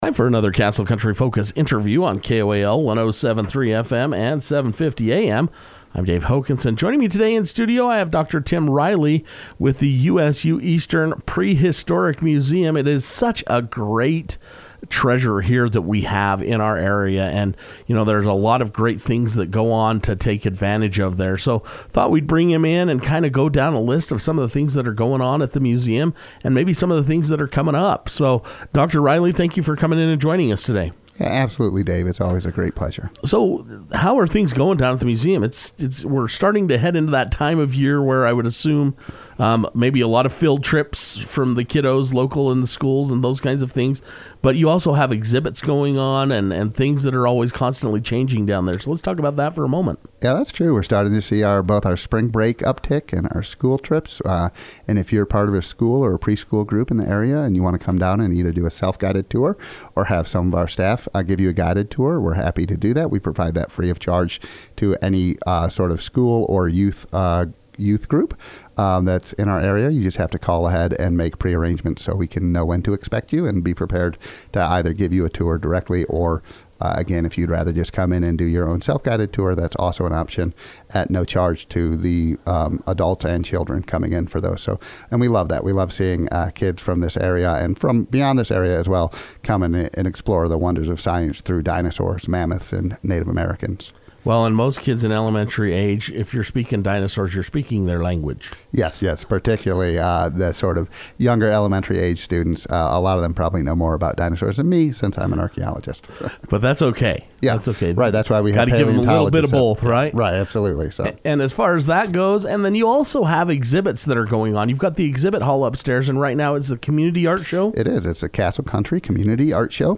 The USU Eastern Prehistoric Museum stopped by Castle Country Radio to talk about their current art exhibit and about upcoming events that will be taking place at the museum.